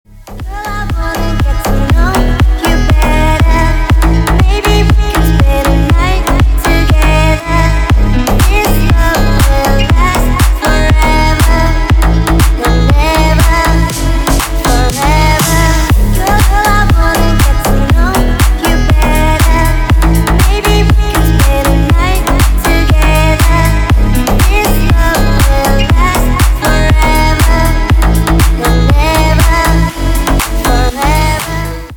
Клубный рингтон 2024 на телефон
• Качество: Хорошее